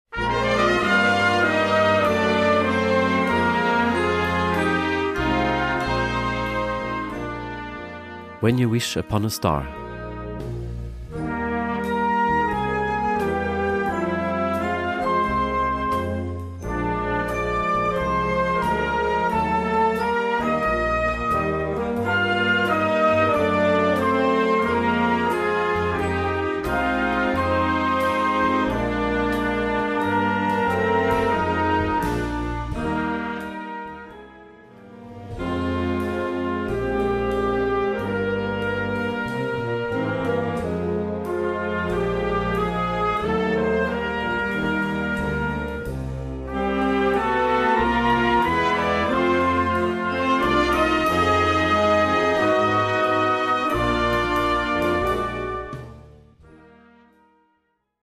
Filmmusik für Jugendblasorchester
Besetzung: Blasorchester